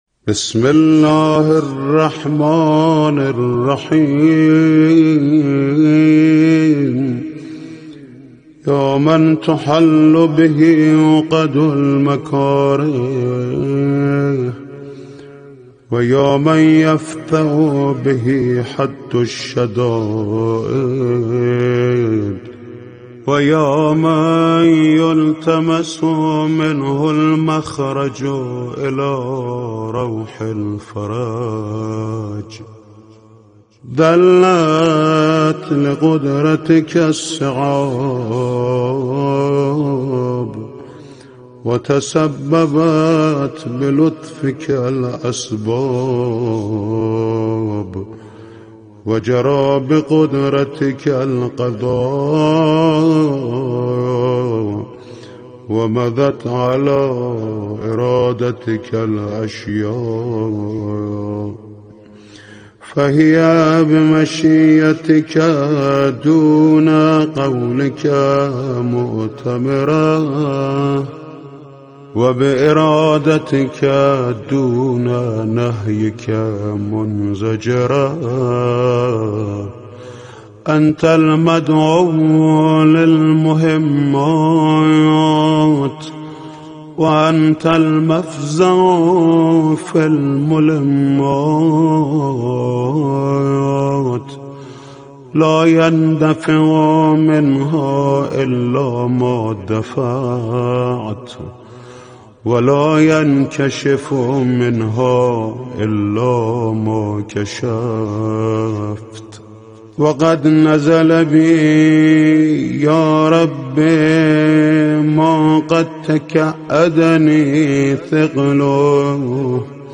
صوت/ دعای هفتم صحیفه سجادیه با نوای محمود کریمی
از آقای کریمی با صدای دلنشین واقعا درتمام عرصه های مذهبی ممنونم